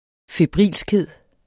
Udtale [ feˈbʁiˀlsgˌheðˀ ]